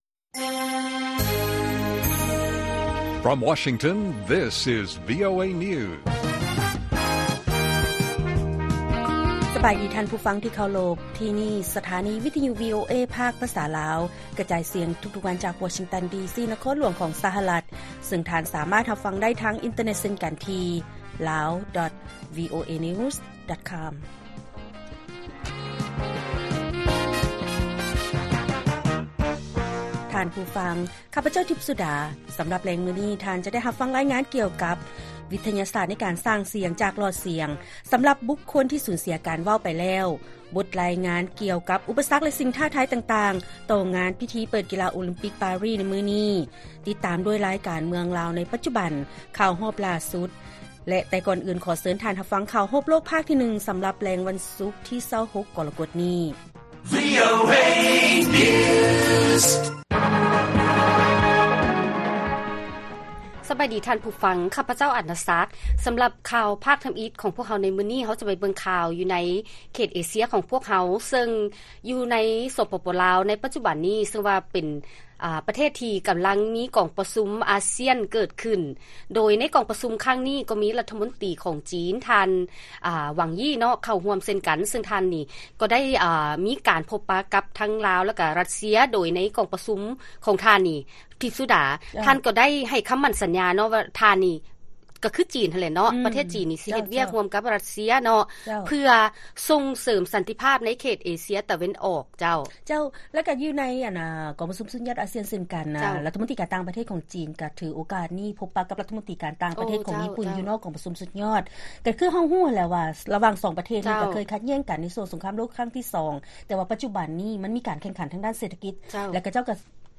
ລາຍການກະຈາຍສຽງຂອງວີໂອເອ ລາວ: ນຶ່ງມື້ກ່ອນພິທີເປີດມະຫະກຳລິກາ ໂອລິມປິກ ຢູ່ ນະຄອນຫຼວງ ປາຣີ, ບັນດາເຈົ້າໜ້າທີ່ກ່າວວ່າ ປາຣີ ພ້ອມແລ້ວ.